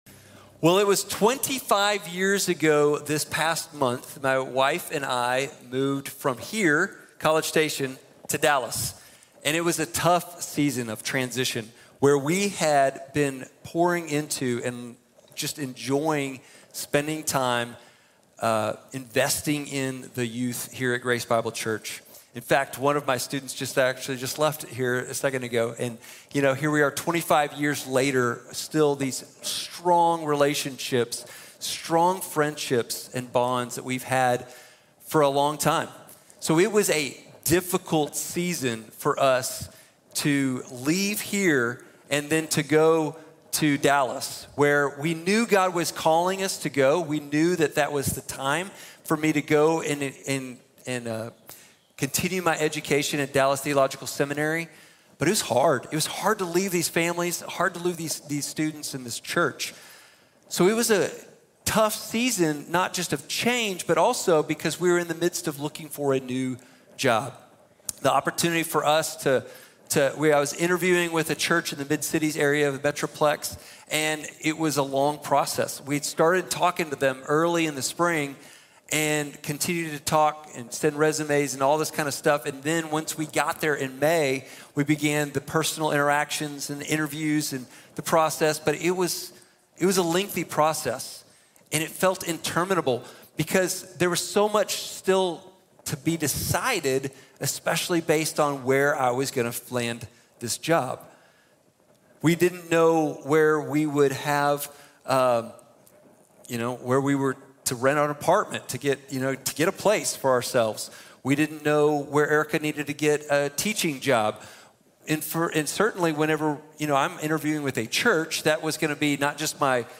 Moses | Sermon | Grace Bible Church